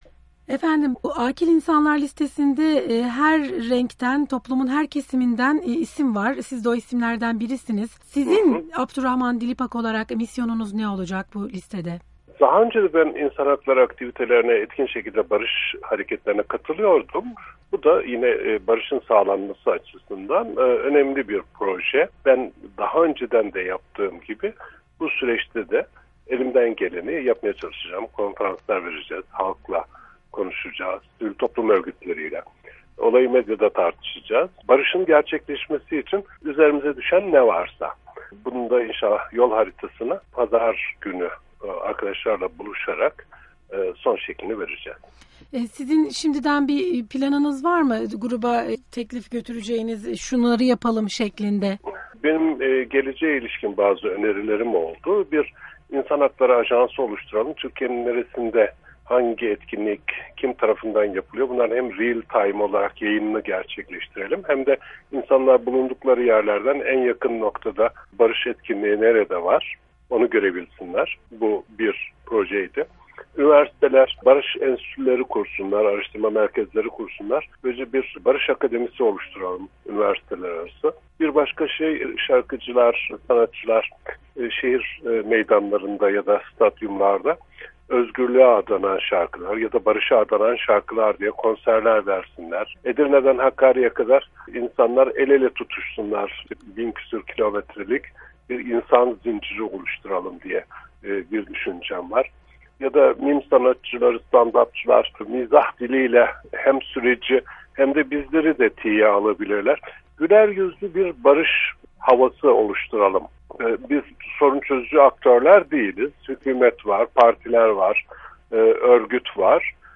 Abdurrahman Dilipak ile Söyleşi